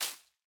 Minecraft Version Minecraft Version snapshot Latest Release | Latest Snapshot snapshot / assets / minecraft / sounds / block / leaf_litter / place2.ogg Compare With Compare With Latest Release | Latest Snapshot